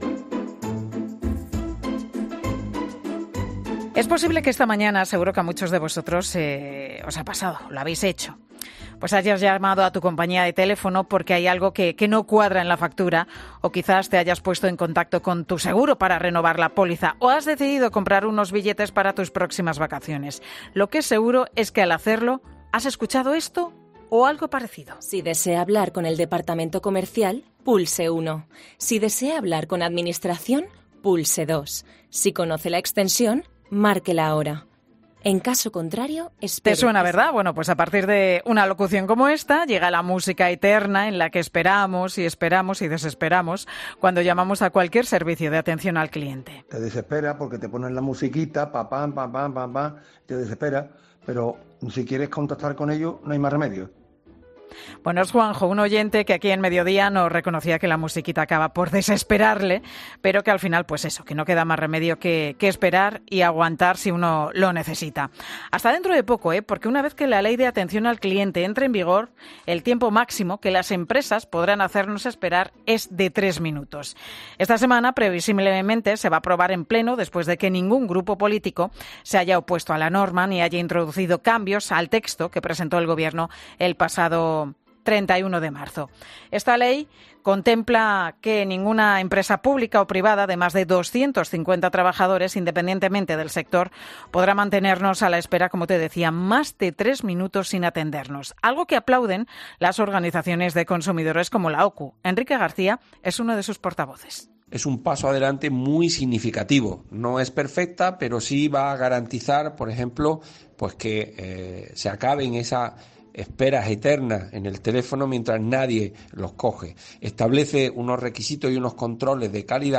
Algo que aplauden las organizaciones de consumidores como la OCU.